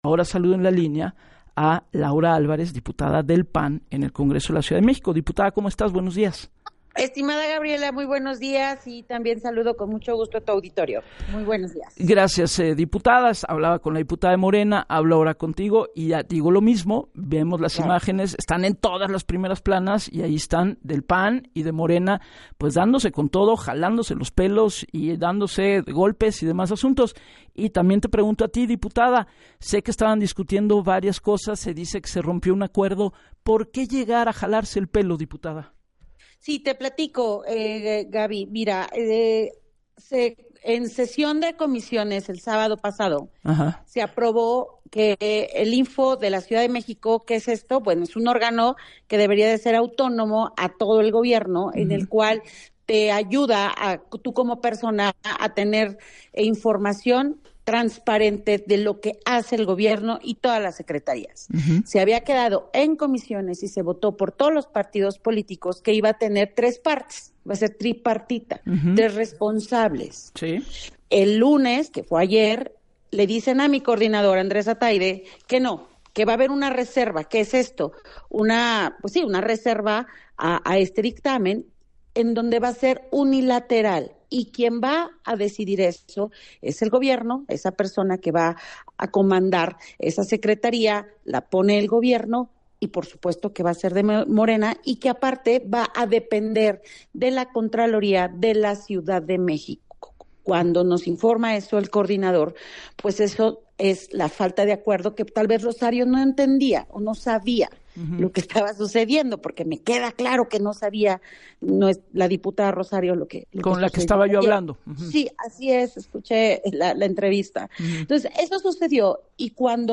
La diputada recordó en entrevista para “Así las Cosas” con Gabriela Warkentin, que el sábado en Comisiones, se aprobó que el INFO CDMX sería un órgano tripartito, sin embargo “ayer le dicen al Coordinador de PAN, Andrés Atayde que habría una reserva y que el INFO sería unilateral y dependiente de la contraloría de CDMX”, cosa que dijo “tal vez Rosario no sabía esto”, refiriéndose a la diputada de Morena que les agredió.